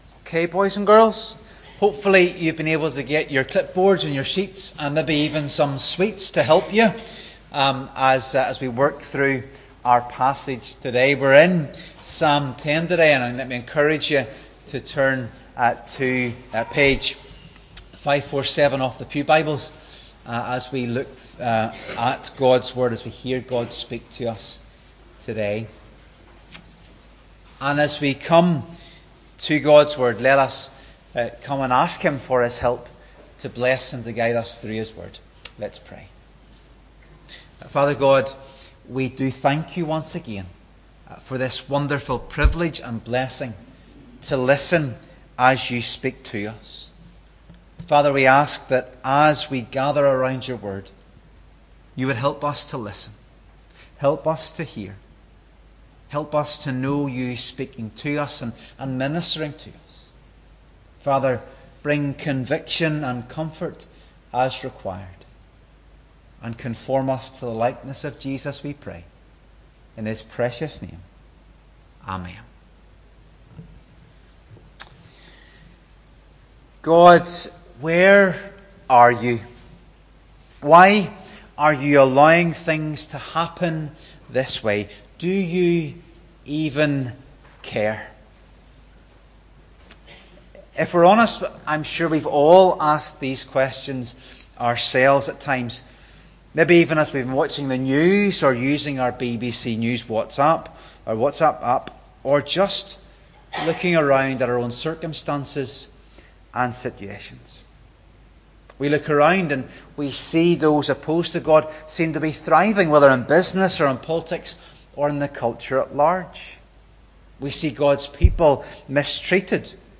Psalm 10:1-18 Service Type: Sunday Morning Worship Psalm 10